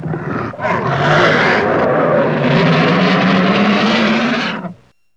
Index of /90_sSampleCDs/E-MU Producer Series Vol. 3 – Hollywood Sound Effects/Water/Alligators
GATOR GRO01L.wav